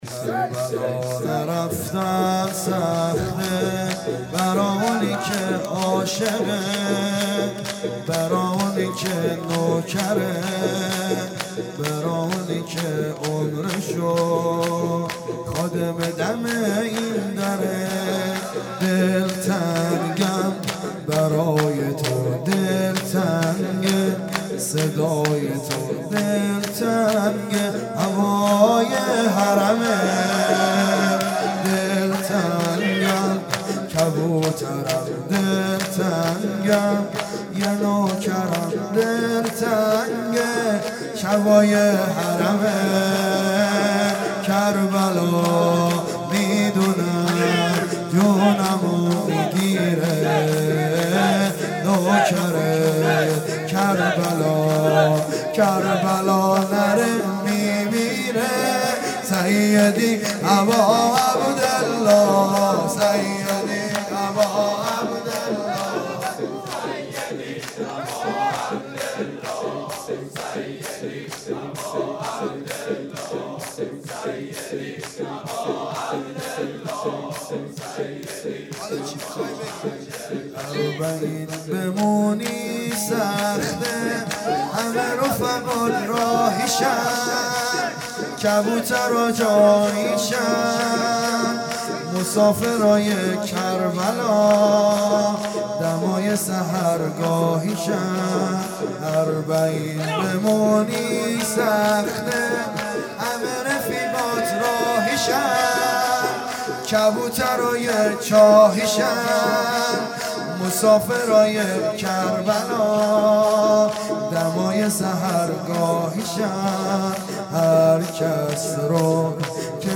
هیئت دانشجویی فاطمیون دانشگاه یزد
شب چهارم محرم